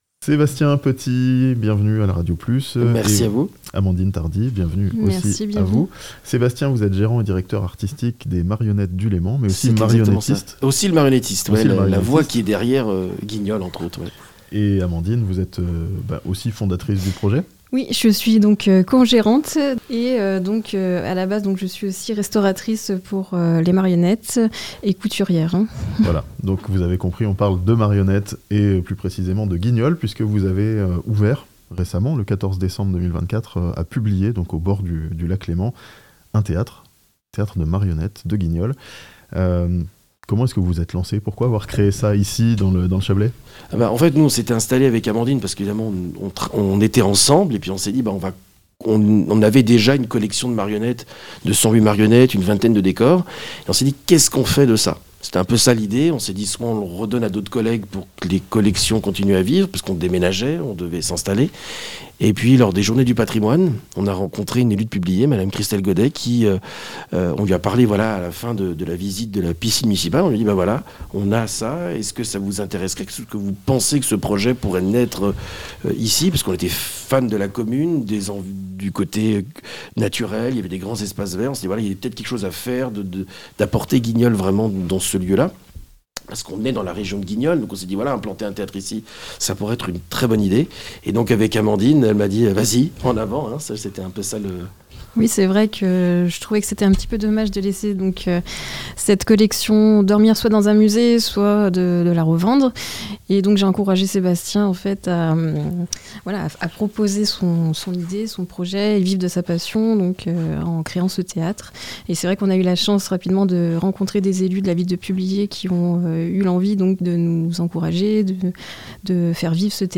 La ville de Publier a désormais son théâtre municipal de Guignol (interview)